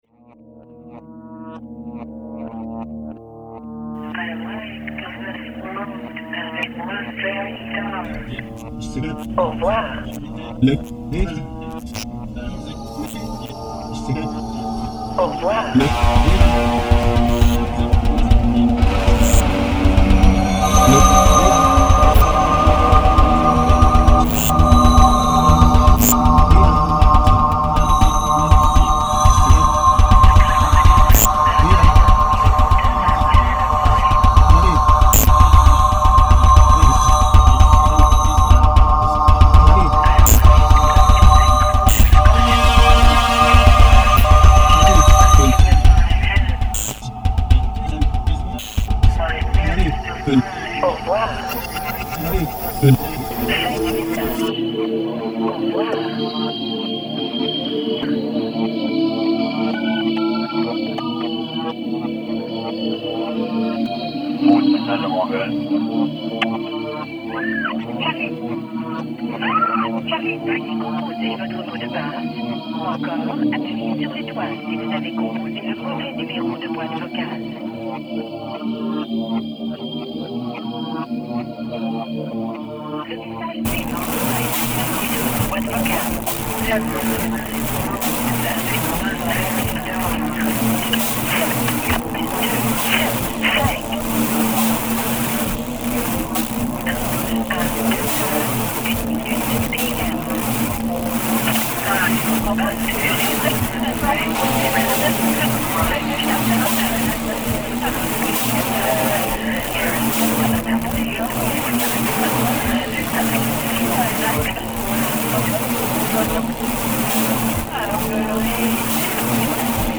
Genre: Experimental